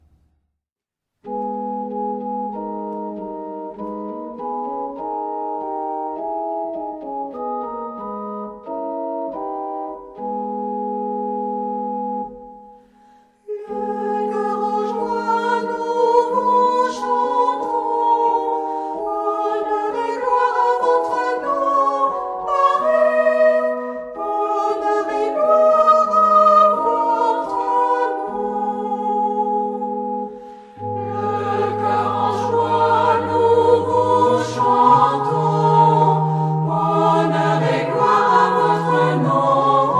Género/Estilo/Forma: Sagrado ; Himno (sagrado)
Carácter de la pieza : calma
Tipo de formación coral: SMA  (3 voces Coro femenino )
Tonalidad : sol sostenido menor